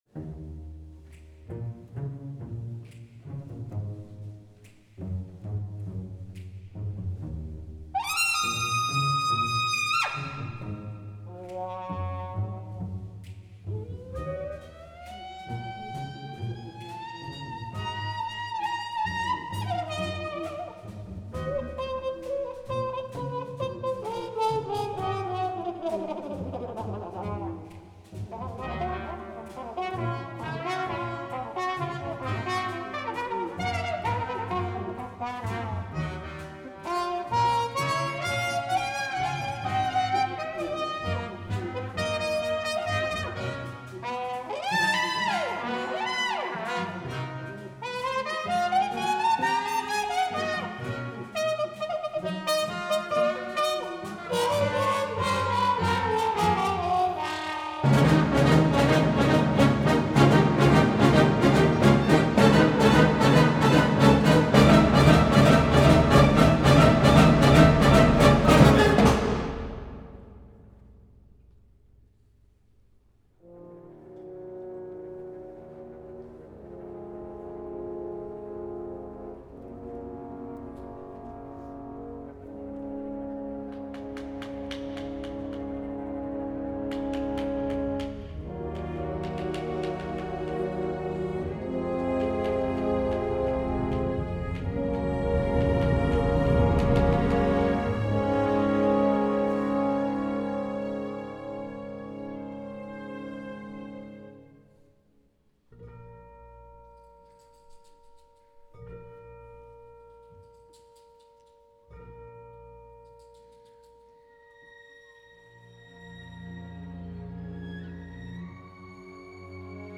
trumpet